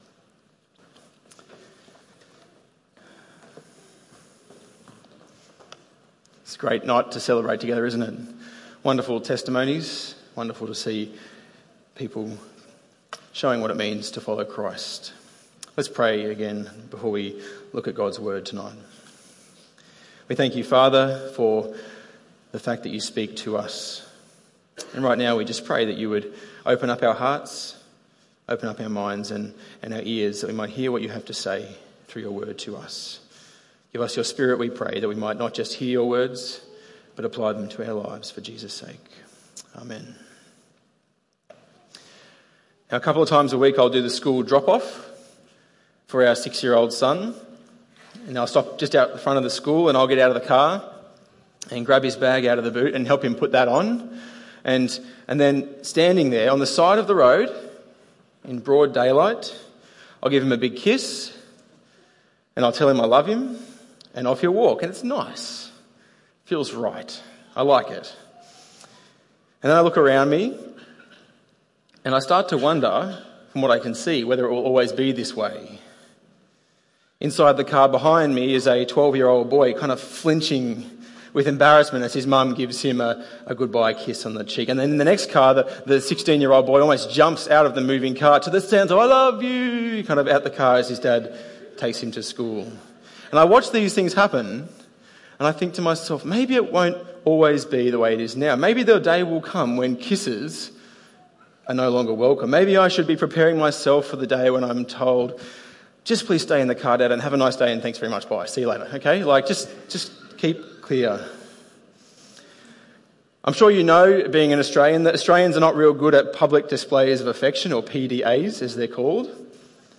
Baptism Service: Public displays of affection are not all that common in Australia.